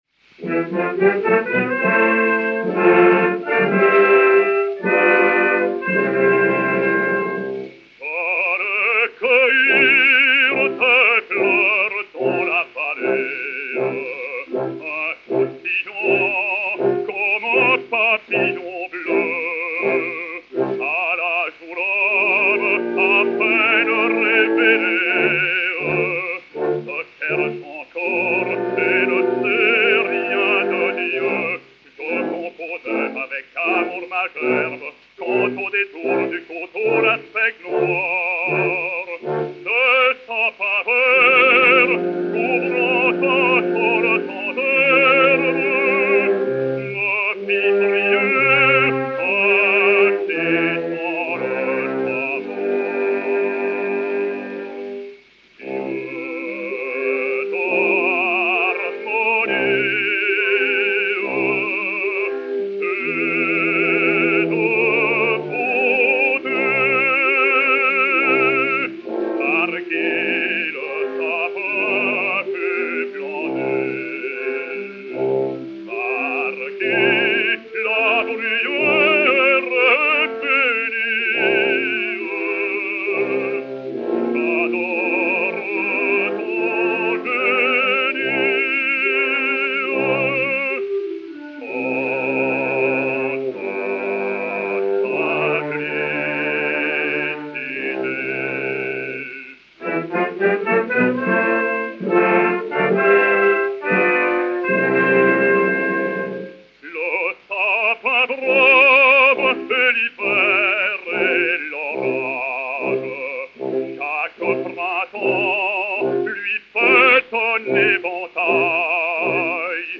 basse française
Orchestre